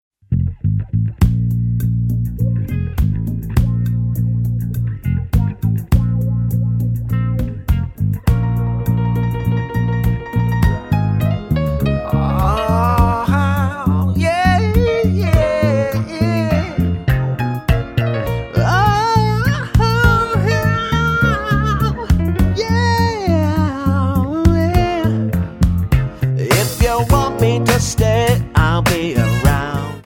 --> MP3 Demo abspielen...
Tonart:Bbm Multifile (kein Sofortdownload.